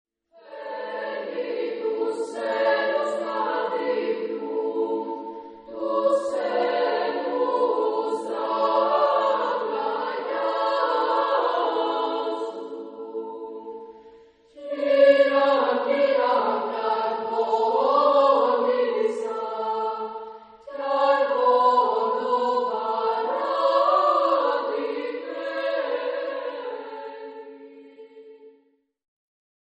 Genre-Style-Form: Christmas carol
Mood of the piece: joyous ; lively
Type of Choir: SSAA  (4 children OR women voices )
Tonality: A major